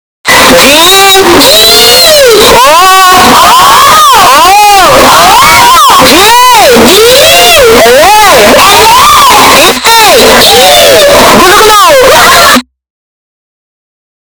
Bass Boosted Google Meme - Botão de Efeito Sonoro